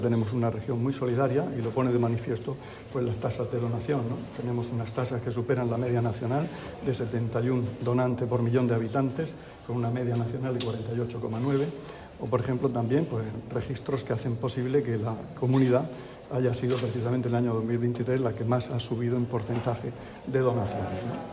Declaraciones del consejero de Salud, Juan José Pedreño, sobre las tasas de trasplantes en la Región [mp3]